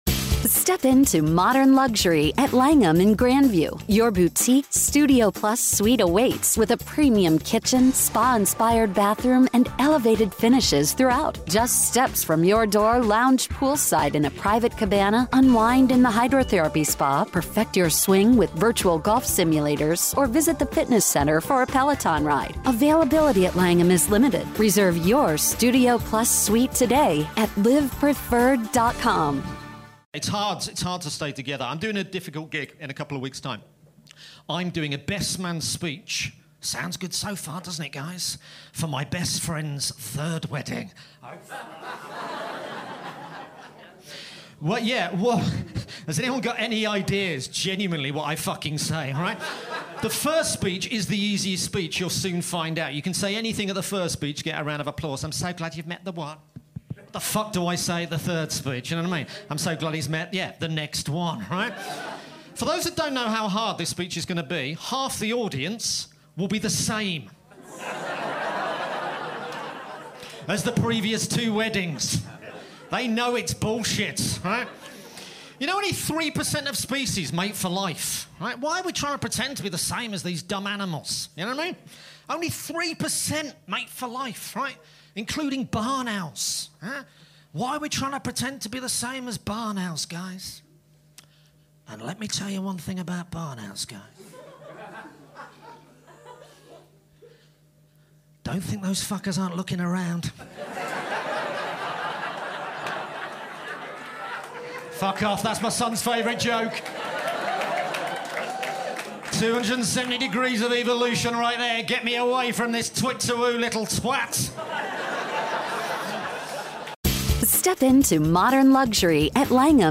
Recorded Live at Backyard Comedy, London, Oct 2023.